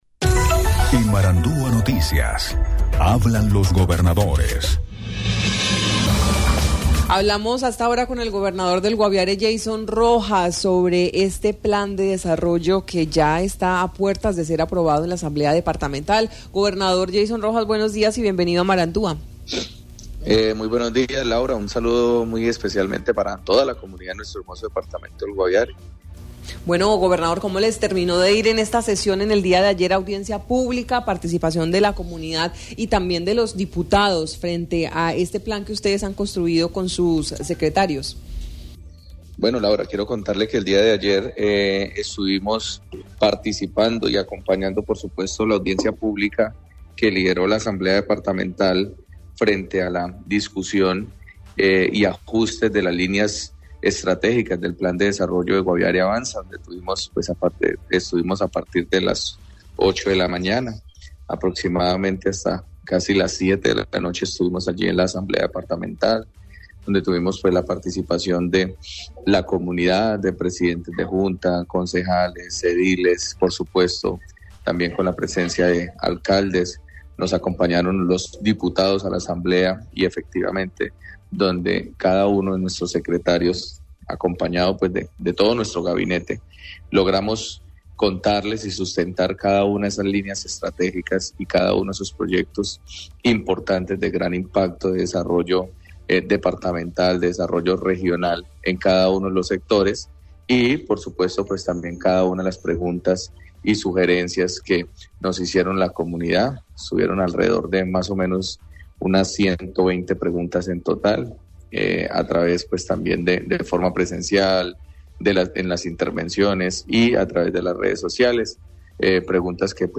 Yeison Rojas, gobernador del Guaviare, habló en Marandua Noticias sobre el ejercicio realizado este martes 28 de mayo dentro del desarrollo de la Audiencia pública, donde se socializó el Plan de Desarrollo “Guaviare Avanza”, destacó el trabajo que su equipo de secretarios ha venido junto con los diputados realizando alrededor del Plan.